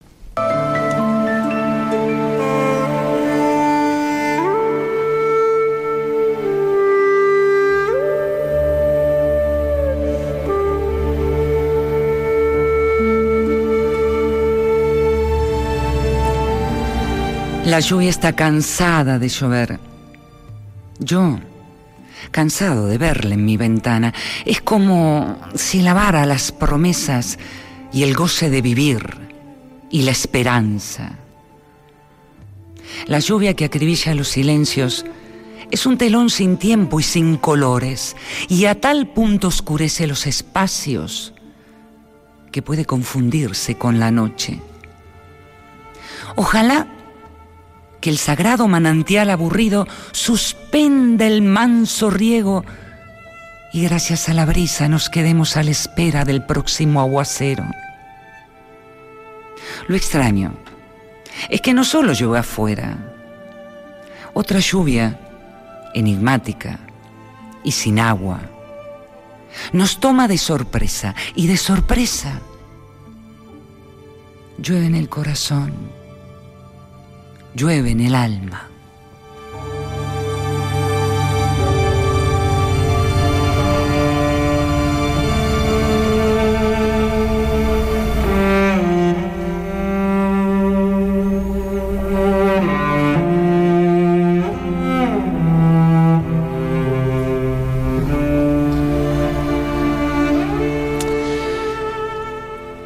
Rapsodeando ''Lluvia'' de Mario Benedetti